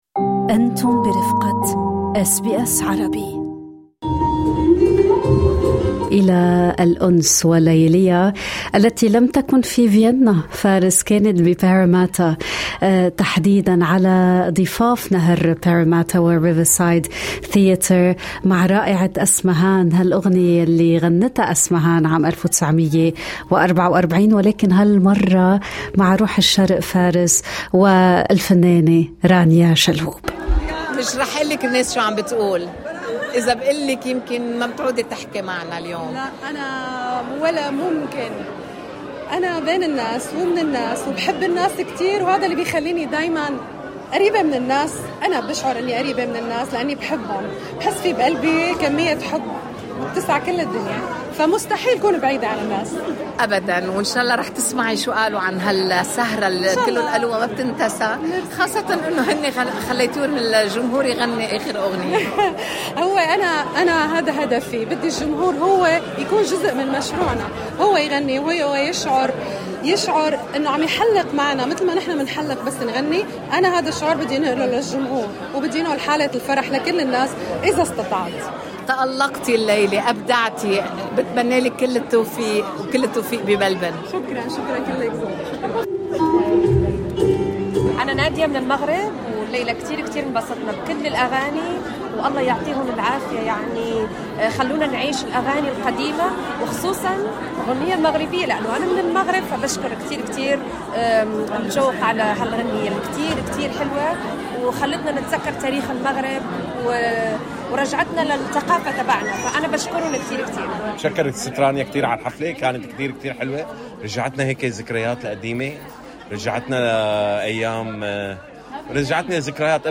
أحييت جوقة روح الشرق حفلًا موسيقيًا حمل عنوان "ليالي الأنس" في سيدني على أن تقدّم الأمسية ايضًا في العاصمة الثقافيّة ملبورن.